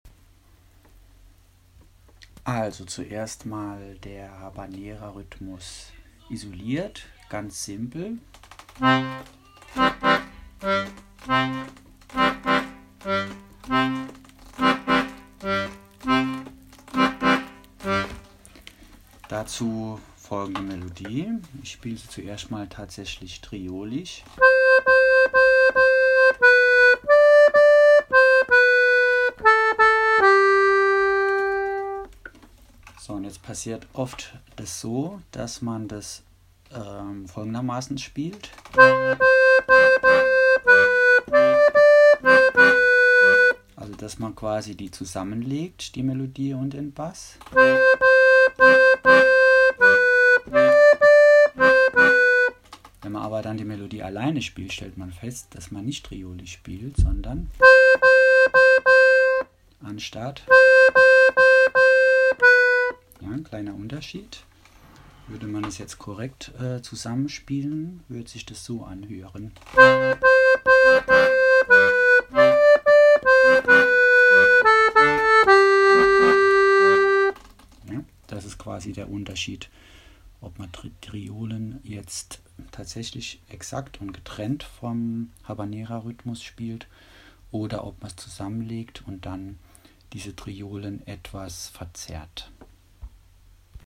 Hier eine ganz simple Aufnahme von Habanera vs Vierteltriolen in La Paloma. PS: Irgendwie geht der direkt abspielbare Eintrag nicht Anhänge La Paloma_demo.mp3 1,4 MB